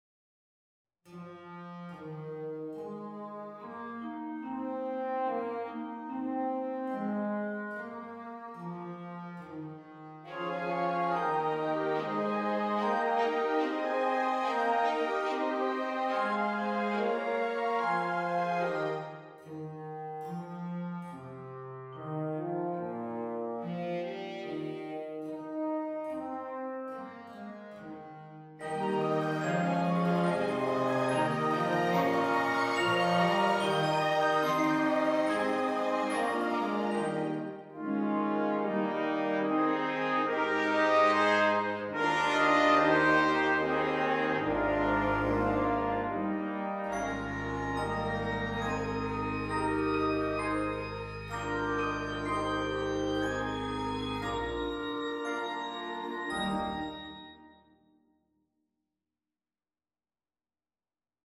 Large Ensemble:
a sort of miniature orchestra